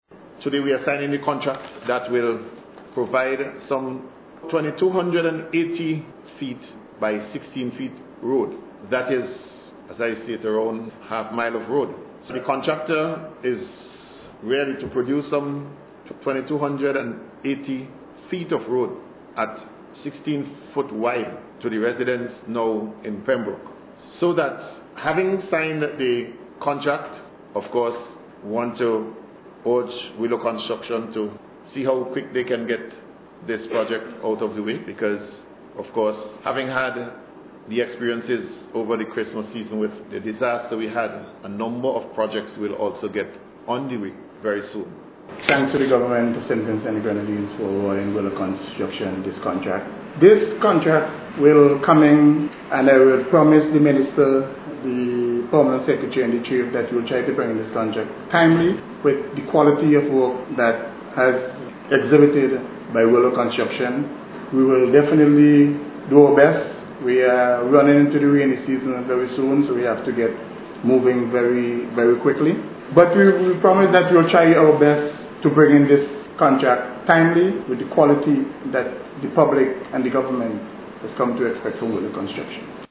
Speaking at the signing ceremony, Minister of Housing Montgomery Daniel said the project is expected to provide about a half a mile of road to the residents in Pembroke. https